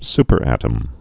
(spər-ătəm)